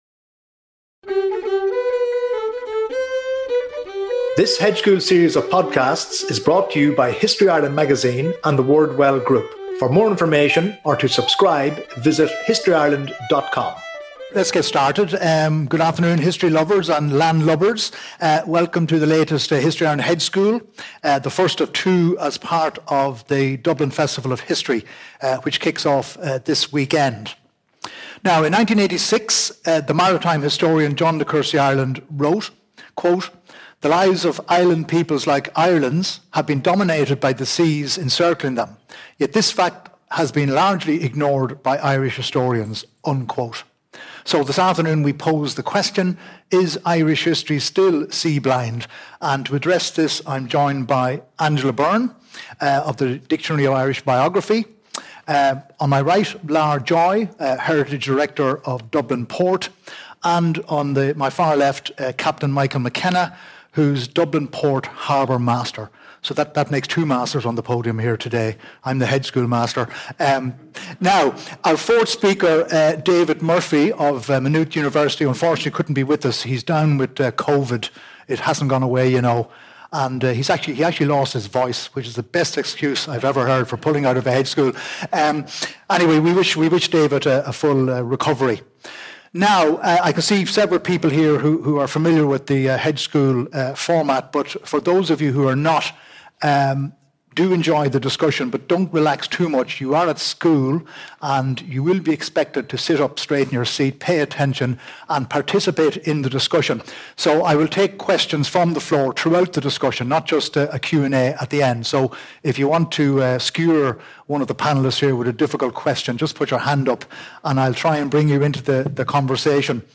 (Recorded live on Sunday 29 September 2024, @ The Substation, Alexandra Road, Dublin, D01 H4C6)